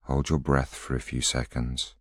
a voice sample is a single voiceover statement which can be used to compose voice collections.